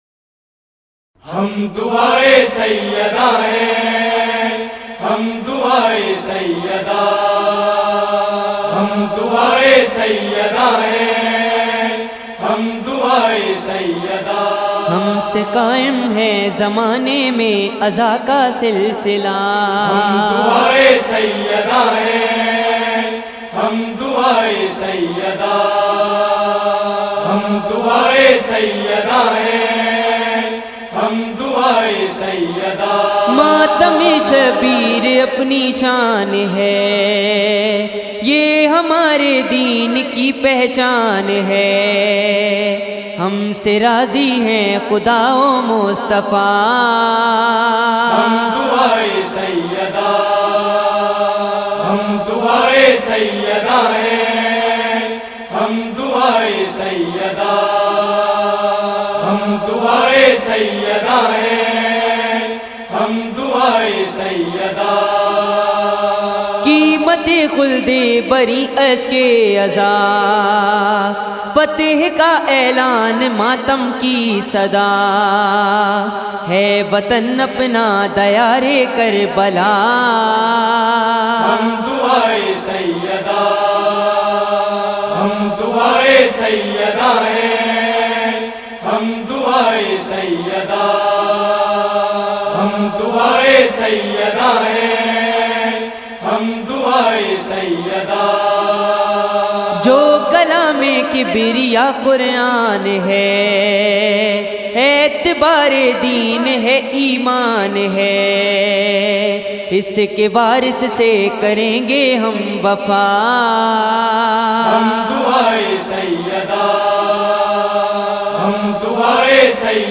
منقبتیں اور قصیدے